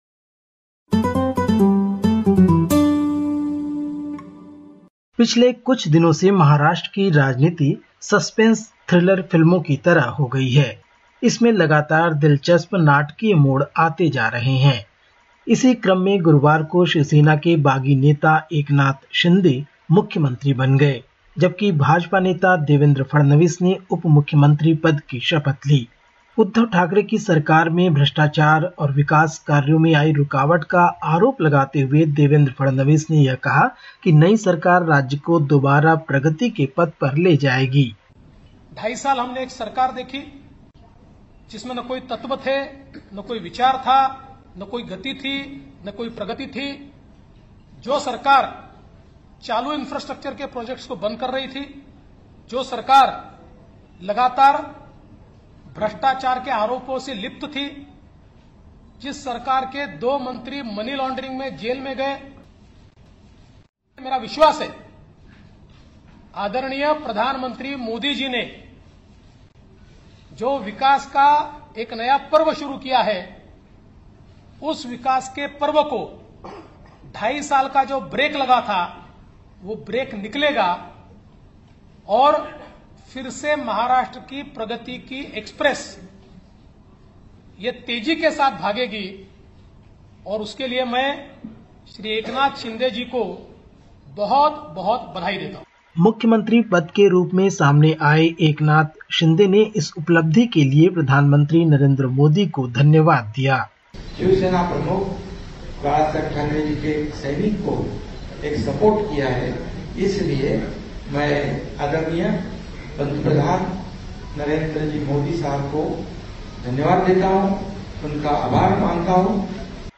Listen to the latest SBS Hindi report from India. 01/07/2022